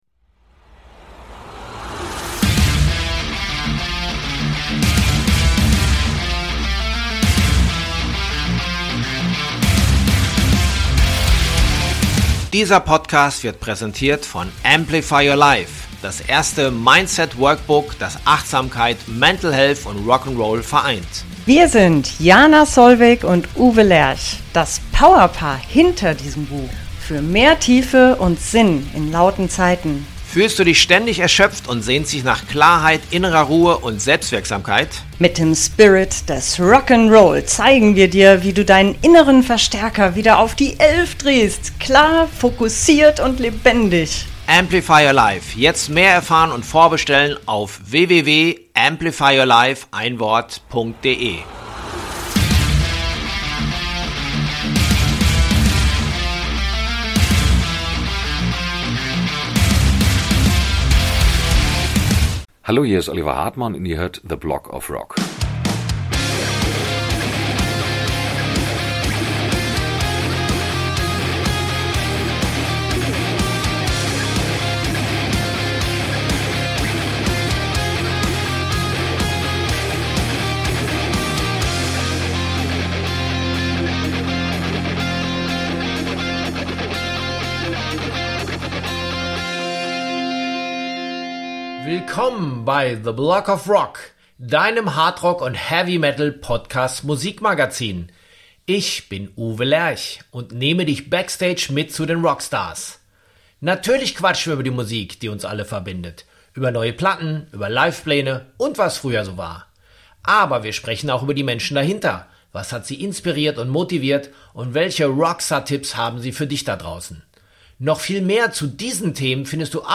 Ich erwischte ihn in den Studios 301 in Frankfurt zum Besuch.
THE BLOG OF ROCK ist Dein Hardrock & Heavy Metal Podcast Musik Magazin.